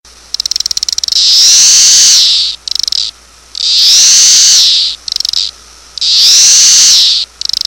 Insects Cicada-sound-HIingtone
insects-cicada-2.mp3